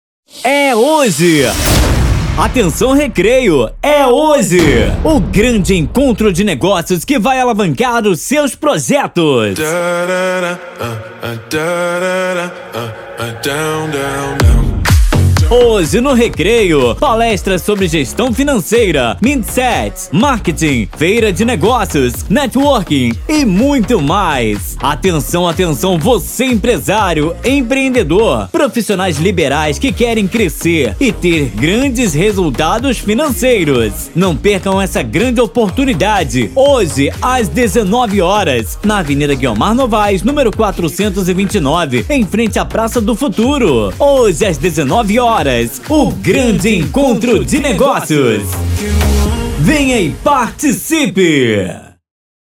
Todos os áudios são produzidos e renderizados na mais alta qualidade e convertidos para o formato que melhor atender suas necessidades.